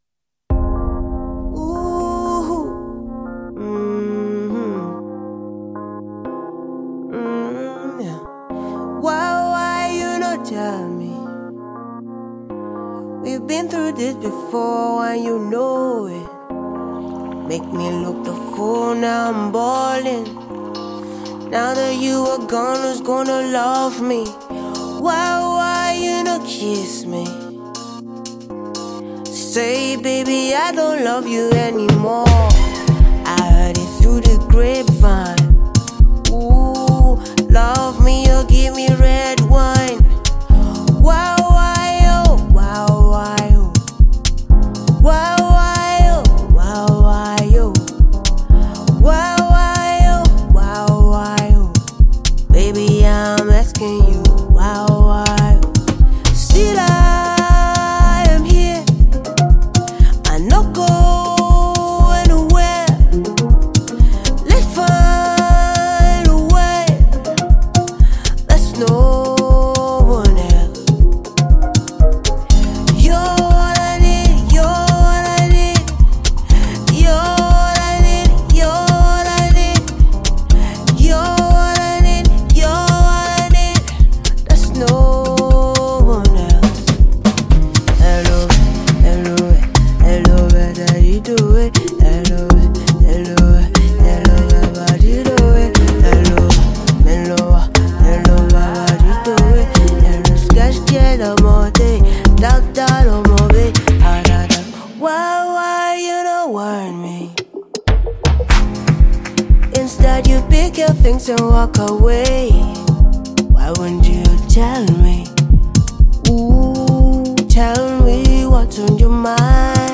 Nigerian female singer